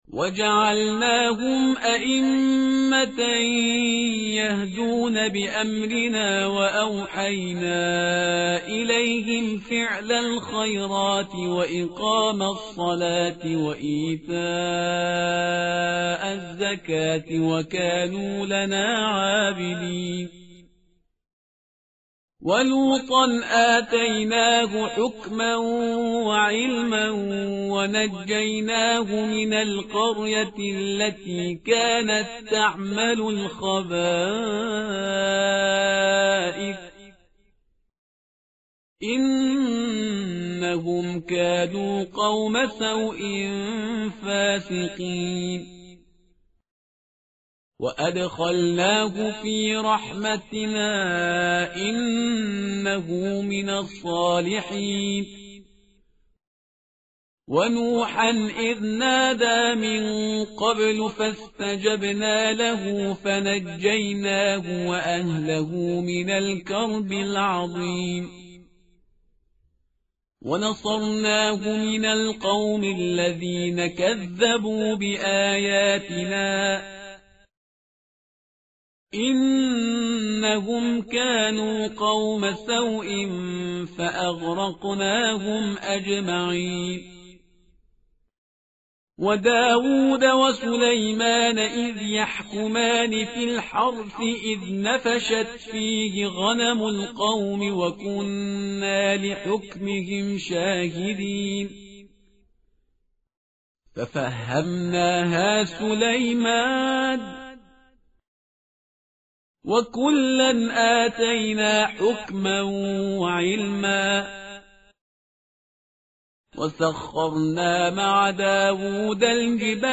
tartil_parhizgar_page_328.mp3